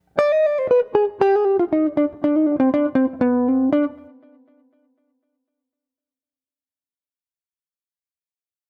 Escala diatônica menor harmônica
Trecho-menor-harmonica.wav